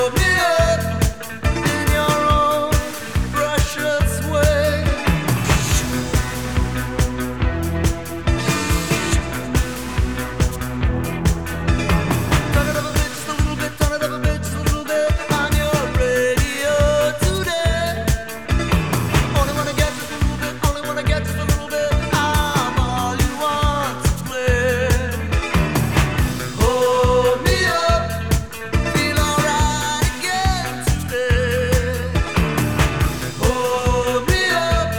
# Поп